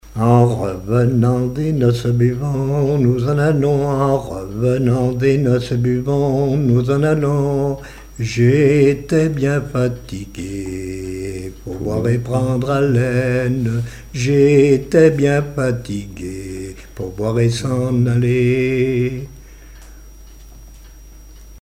gestuel : à marcher
circonstance : fiançaille, noce
Genre laisse